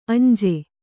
♪ añje